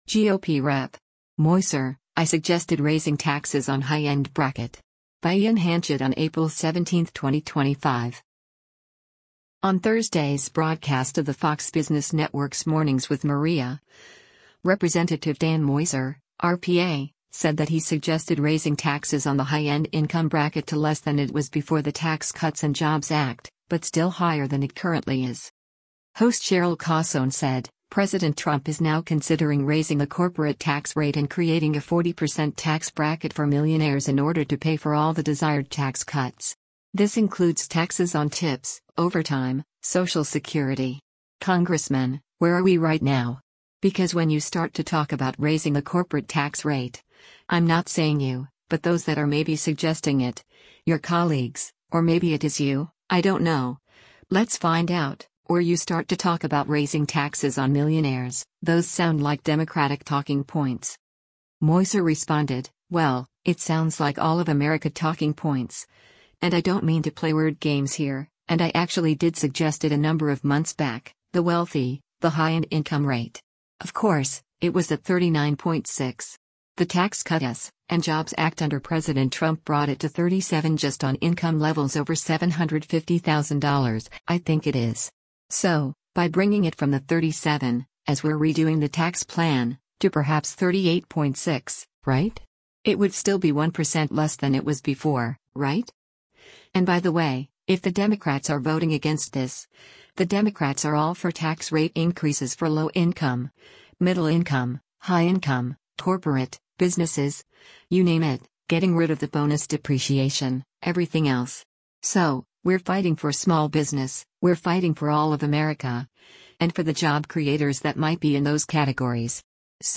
On Thursday’s broadcast of the Fox Business Network’s “Mornings with Maria,” Rep. Dan Meuser (R-PA) said that he suggested raising taxes on the high-end income bracket to less than it was before the Tax Cuts and Jobs Act, but still higher than it currently is.